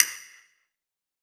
BCASTANET.wav